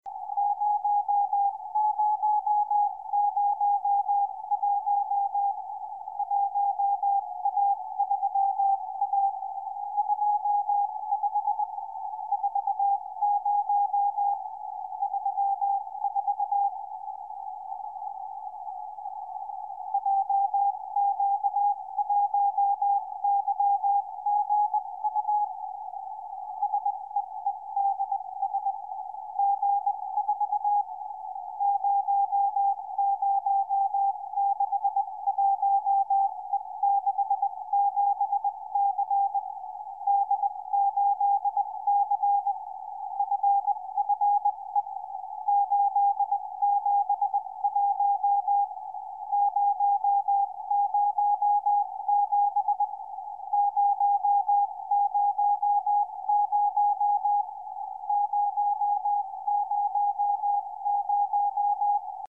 SEEDS II sat. JQ1YGU (telemetria 437,485 Mhz CW)